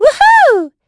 Estelle-Vox_Happy3.wav